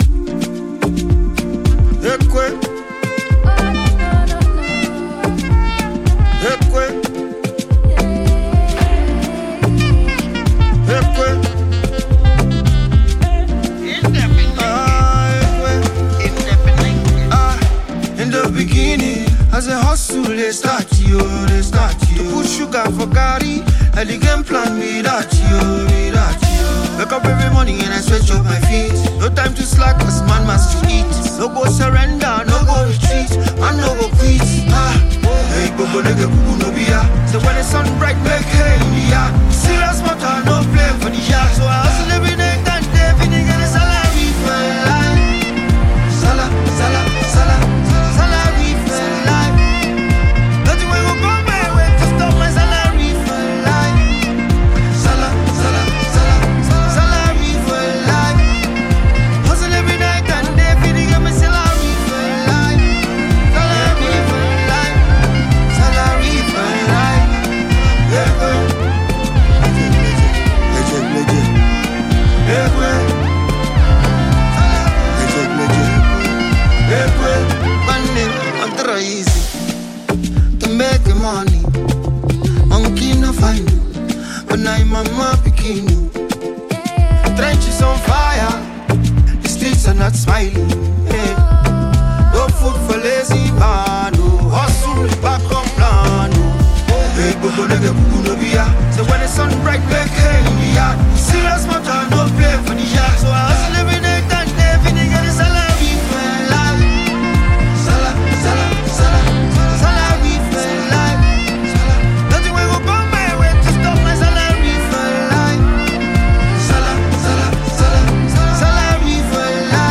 Blending Afropop and Afro-fusion